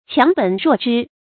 強本弱枝 注音： ㄑㄧㄤˊ ㄅㄣˇ ㄖㄨㄛˋ ㄓㄧ 讀音讀法： 意思解釋： 強干弱枝。